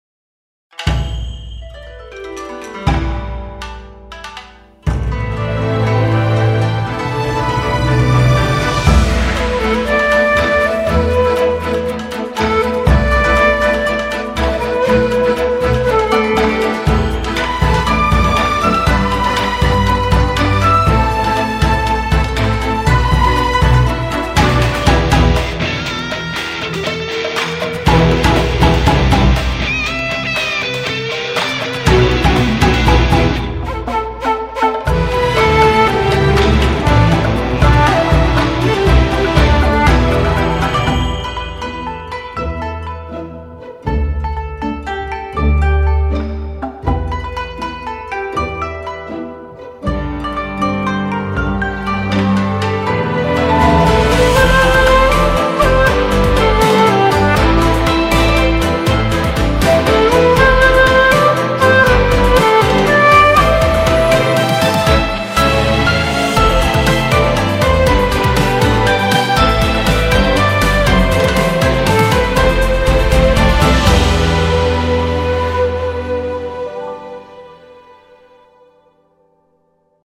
Trailer BGM